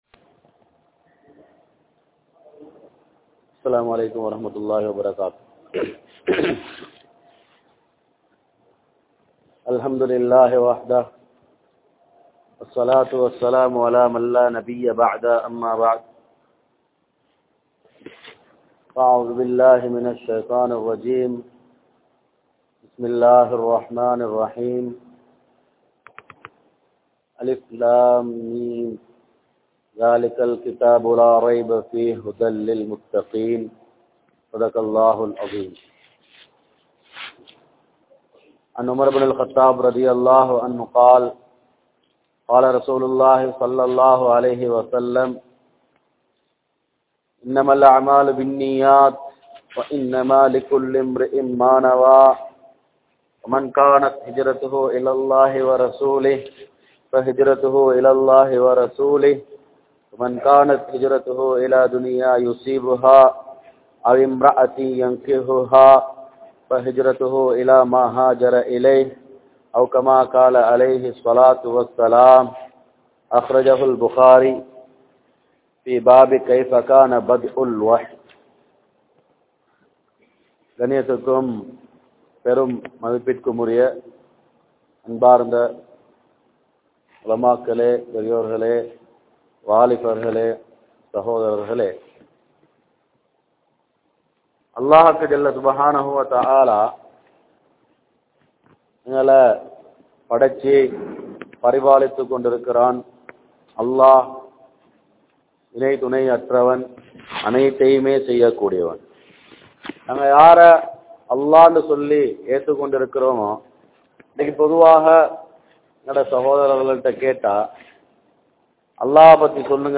Youth & Modern World | Audio Bayans | All Ceylon Muslim Youth Community | Addalaichenai